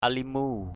/a-li-mu/ (Ar.) ilm علم (d.) khoa học = science. science. ngap gruk hu alimu ZP g~K h~% al|m~% làm việc có khoa học.